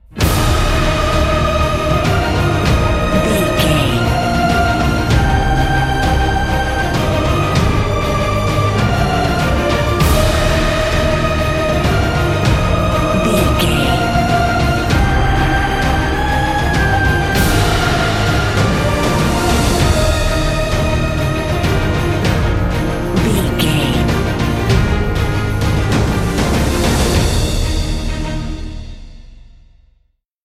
Ionian/Major
E♭
cinematic
brass
strings